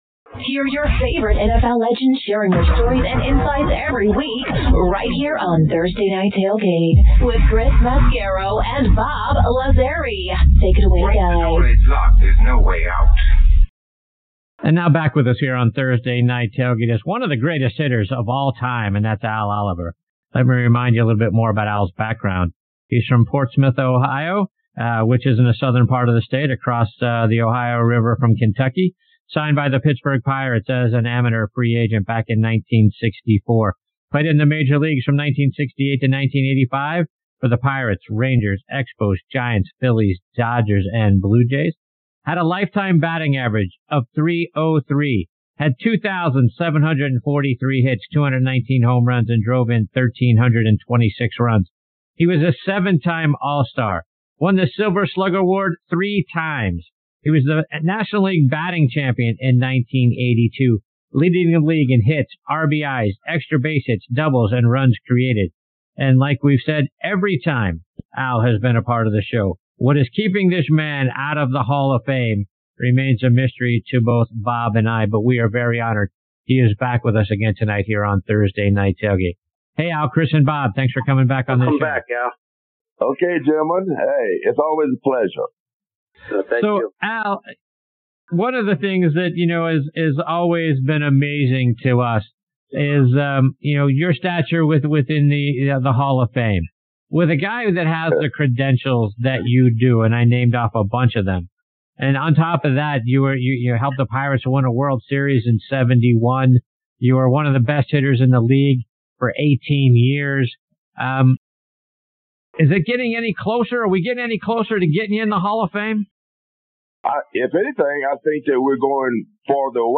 Al Oliver, former Pirates 1B/OF Joins Us on this Segment of Thursday Night Tailgate